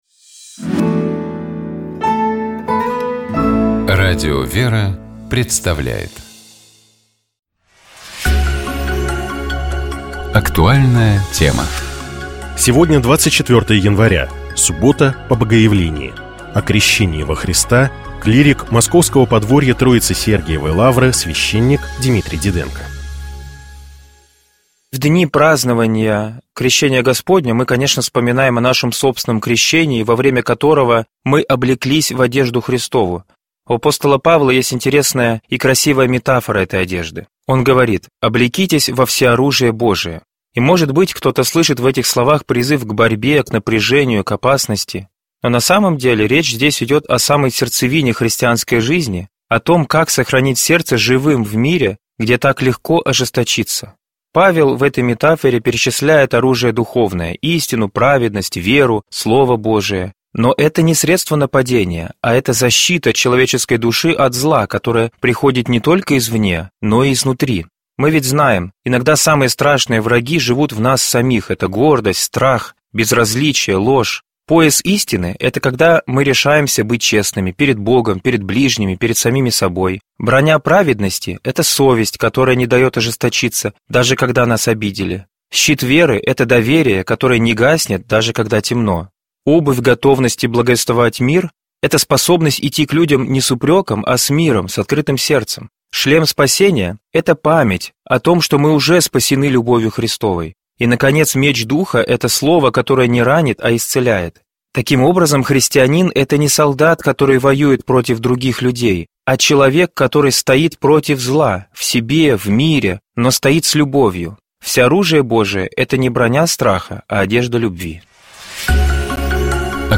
В данной программе звучат избранные главы книги Александра Дмитриевича.